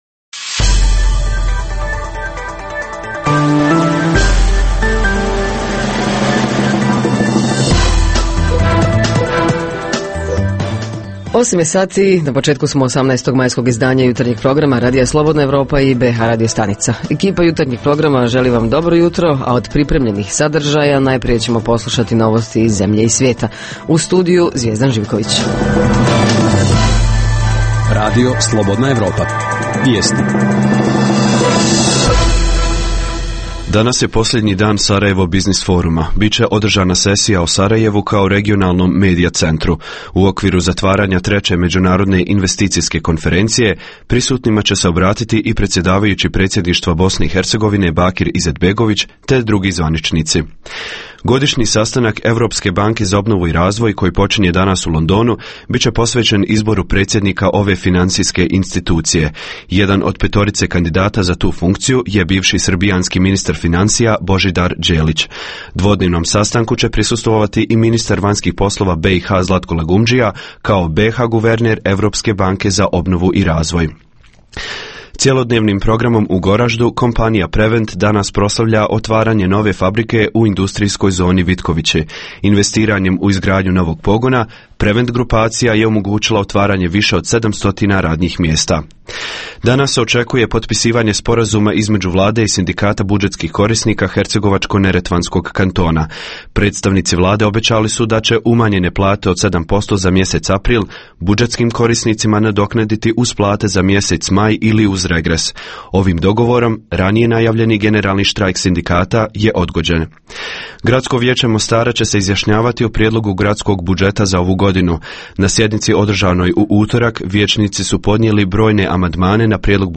Ovog jutra tema je Međunarodni dan muzeja - poslušajte na koji način će biti obilježen ovaj dan u vašem gradu, odnosno šta muzeji pripremaju za građane tim povodom. Reporteri iz cijele BiH javljaju o najaktuelnijim događajima u njihovim sredinama.
Redovni sadržaji jutarnjeg programa za BiH su i vijesti i muzika.